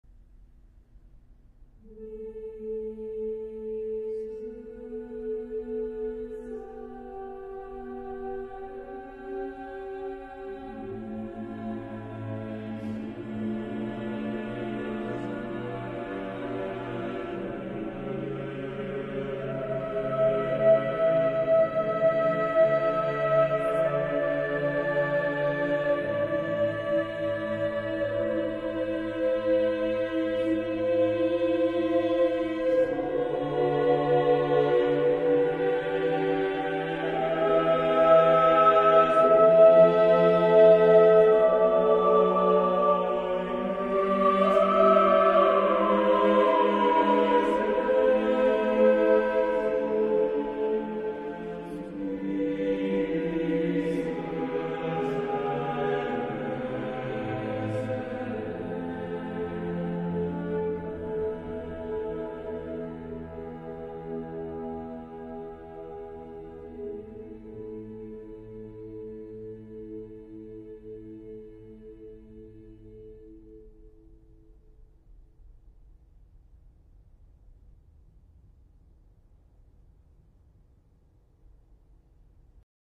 Music Category:      Early Music